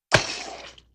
splat8.ogg